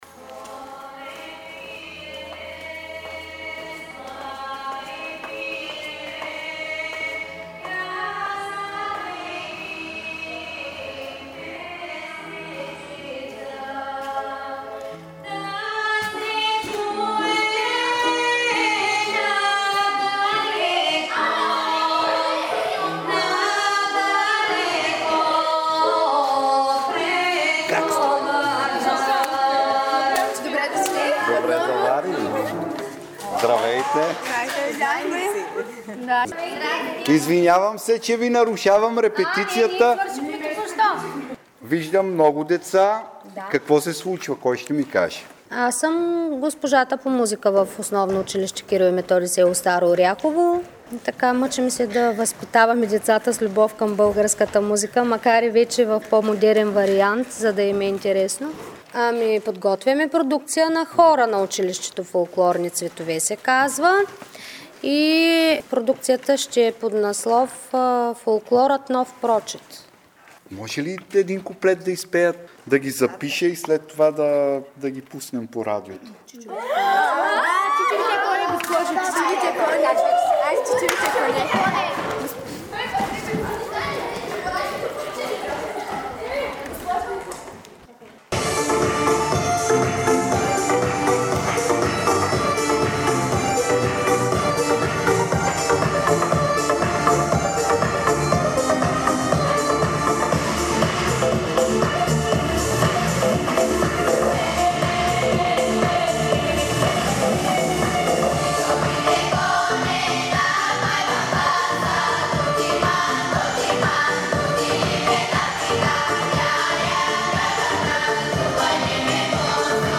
Децата от Старо Оряхово, област Варна не пропускат празниците в селото, защото тогава имат възможност да се включат в празничната програма и да излязат на сцената, за да зарадват своите близки и приятели, като пеят и танцуват.